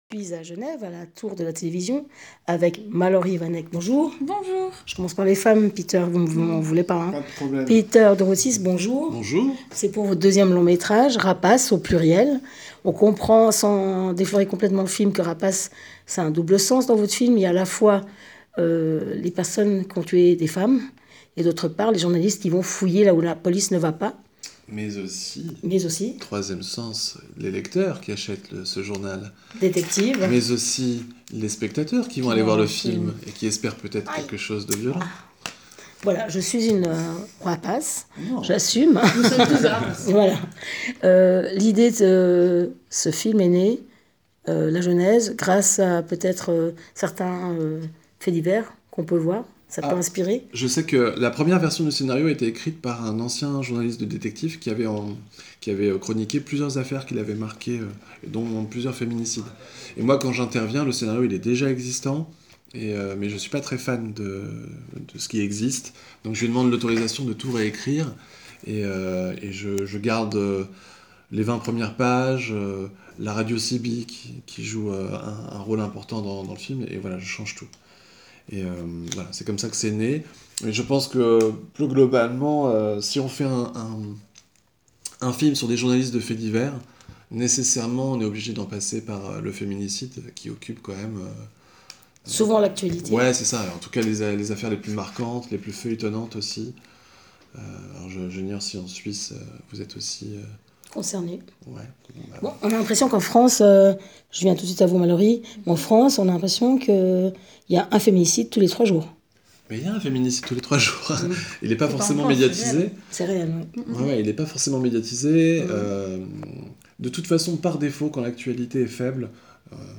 Rencontre - j:mag